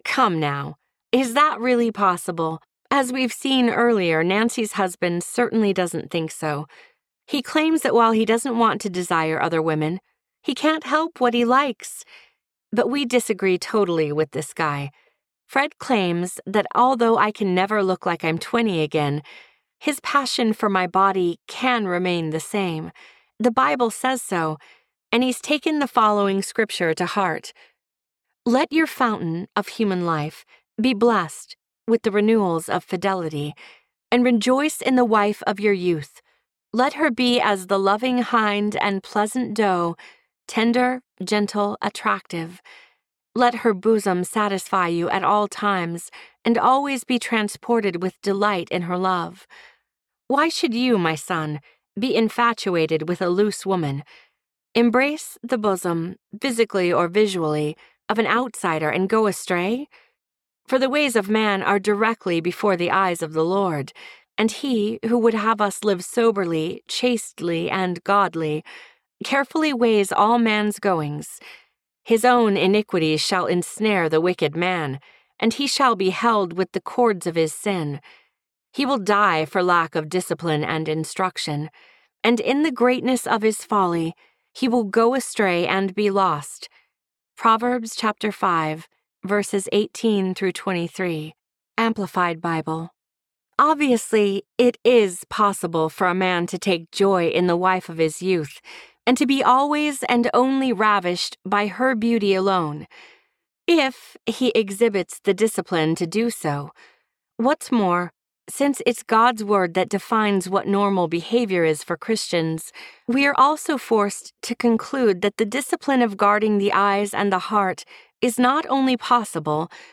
Every Heart Restored Audiobook
11.67 Hrs. – Unabridged